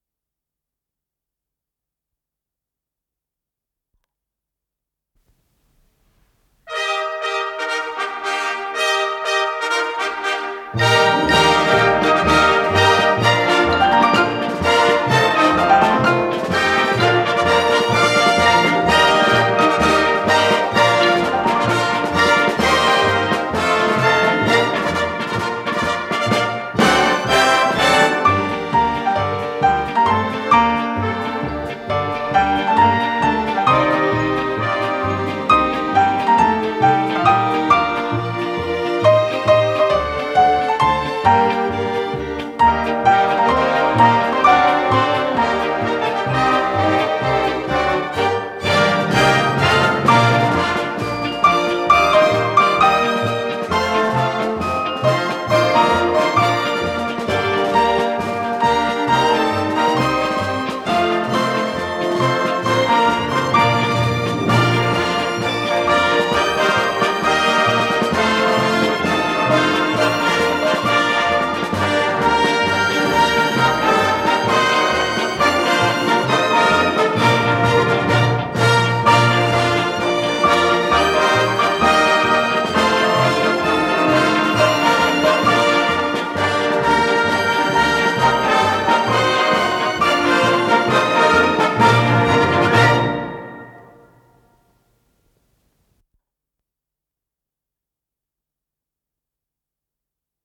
ПодзаголовокЗзаставка, до минор
Скорость ленты38 см/с
ВариантДубль моно